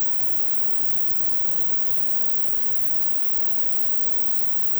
While today’s noize isn’t very musical, It will hopefully be educational.
Typically it lives far below audible levels.
Mbit with noise shaping
Mbit-with-noise-shaping.wav